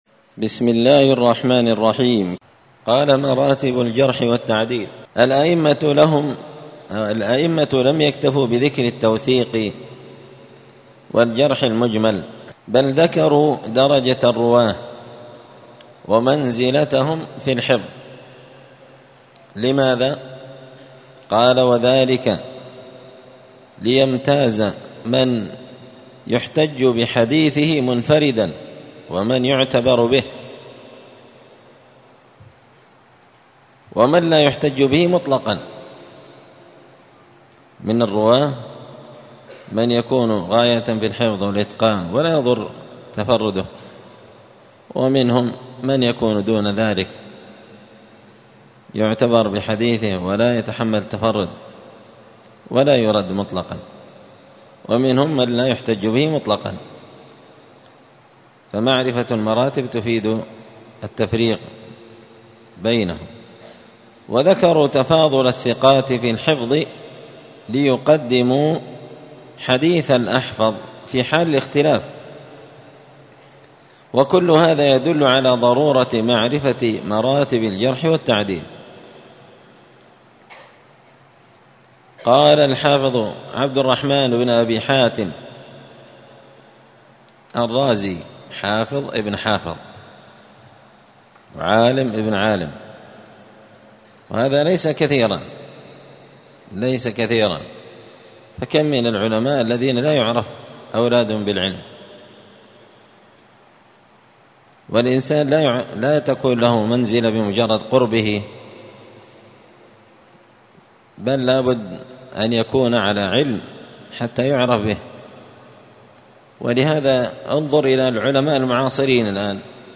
*الدرس الخامس عشر (15) مراتب الجرح والتعديل*